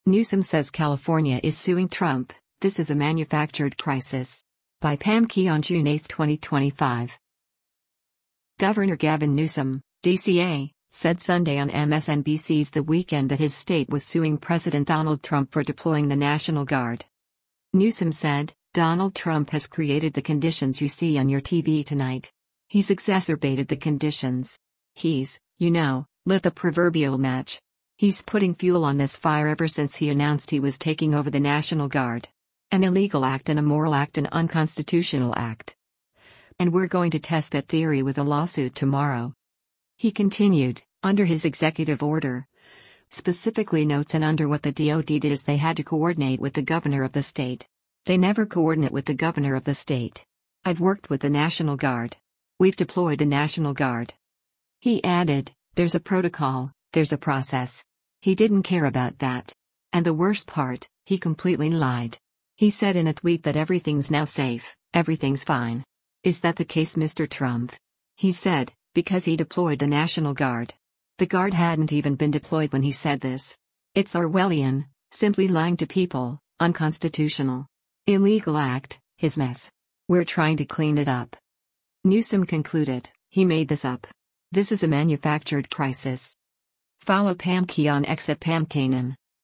Governor Gavin Newsom (D-CA) said Sunday on MSNBC’s “The Weekend” that his state was suing President Donald Trump for deploying the National Guard.